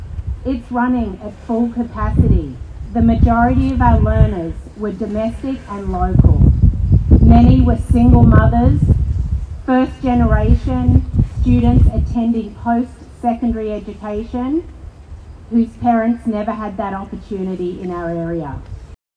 With chants of ‘our college, our future,’ a large number of faculty, support staff, students and union supporters rallied at Loyalist College Wednesday afternoon.
Professors from recently suspended programs made emotional addresses the crowd.